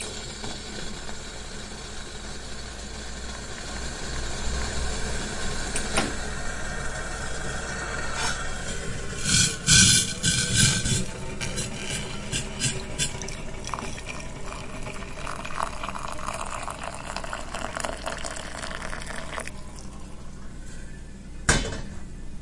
Kettle whistling
描述：kettle's water boiling, whistling kettle on a stove. Recorded with a ZOOMH4n, in the kitchen.
标签： Kitchen boil scream appliances boilingwater hot boiling water steam kettle stove whistle OWI
声道立体声